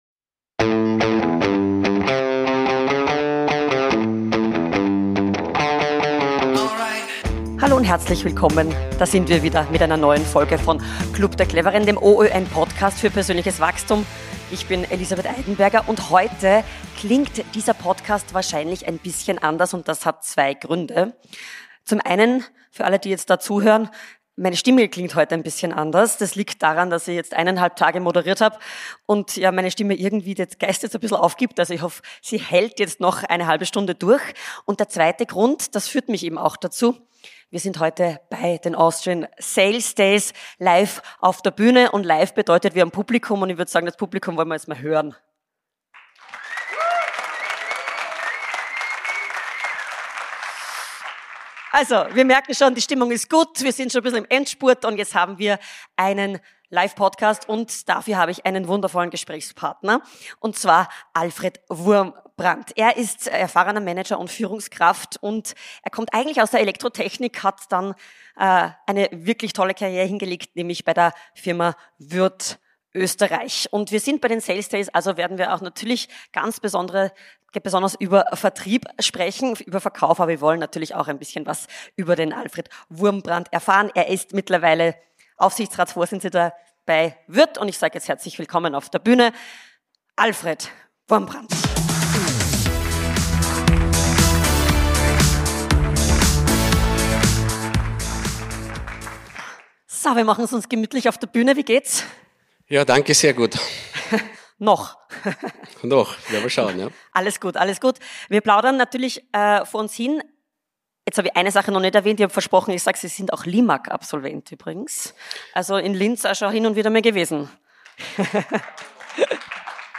Im Live-Podcast bei den Austrian Sales Days